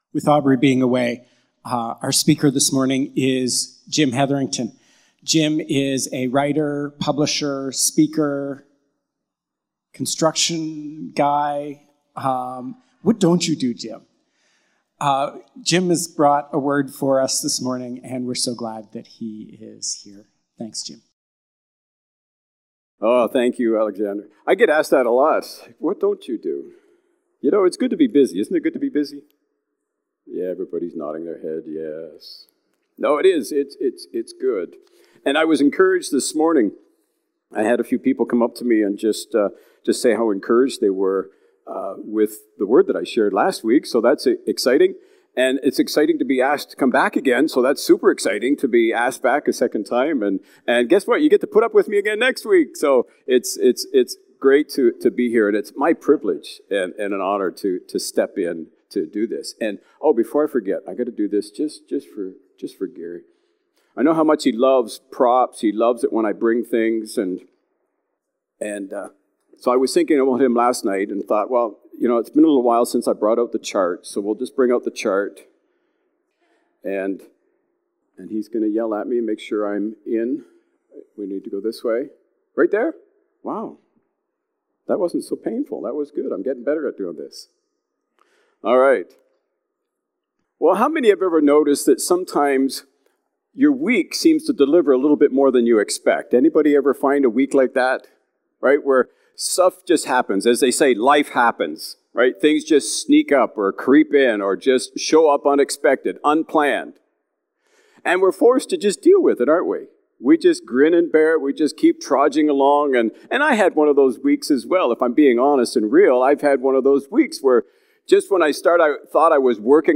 July-14-Sermon.mp3